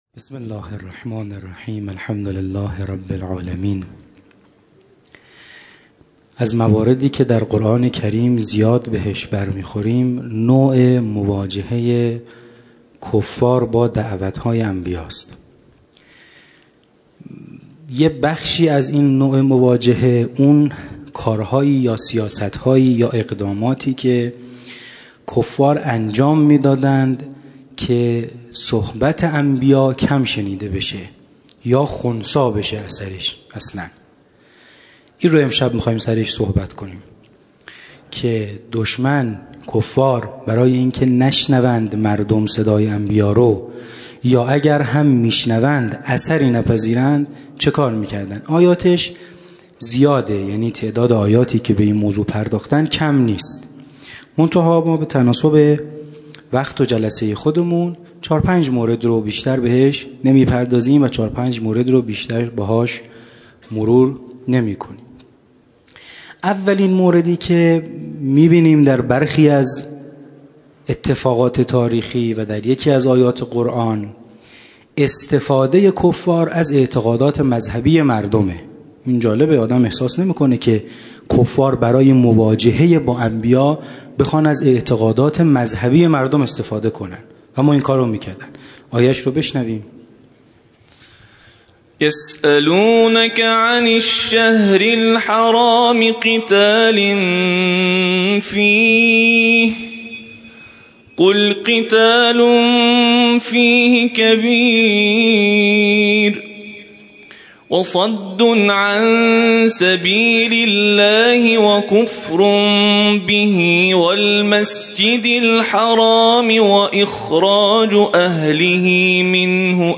همچنین آیات استناد شده در خلال سخنرانی، توسط یکی از قاریان ممتاز دانشگاه به صورت ترتیل قرائت می‌شود.